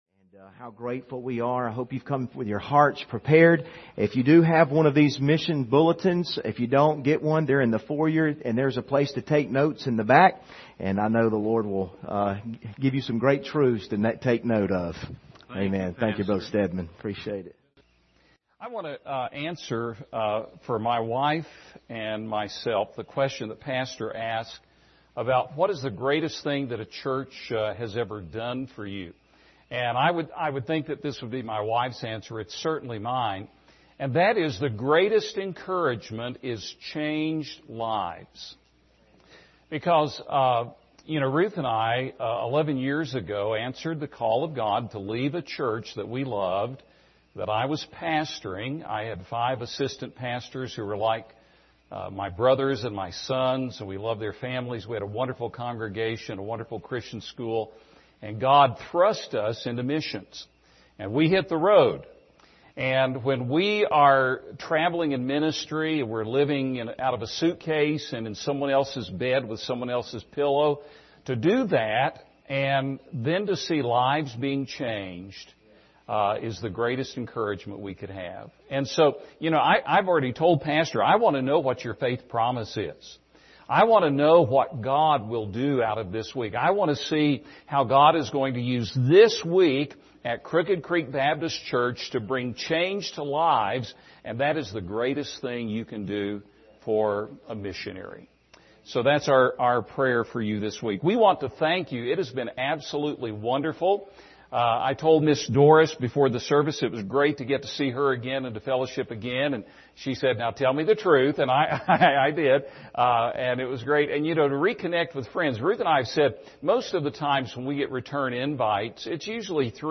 Series: 2020 Missions Conference
Service Type: Special Service